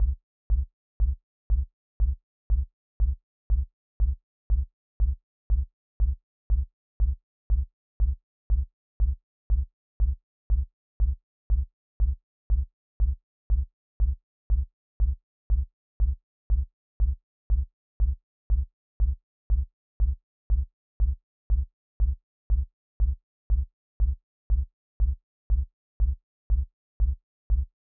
The next sound I created was the a kick drum.
I turned on the filter envelope where I shrunk the release and transposed the pitch down an octave .
I then added the EQ Eight equaliser to eliminate higher frequency sounds occurring.
kick.wav